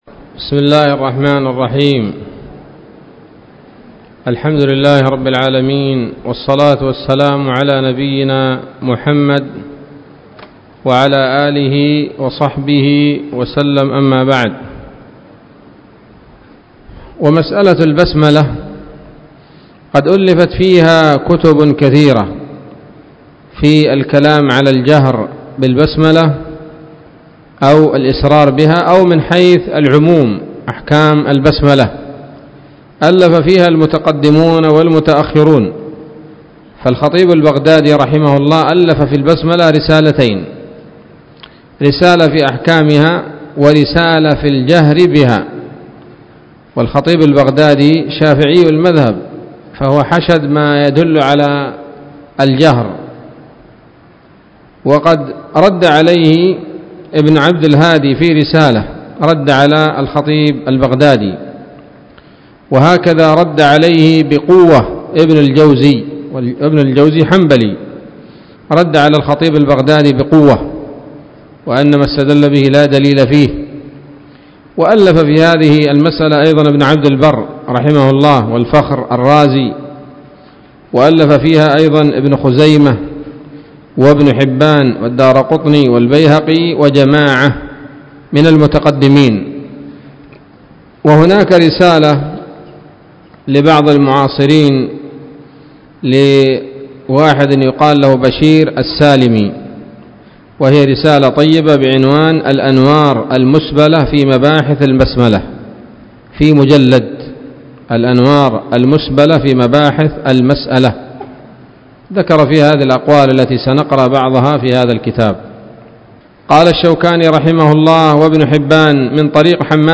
الدرس الحادي والعشرون من أبواب صفة الصلاة من نيل الأوطار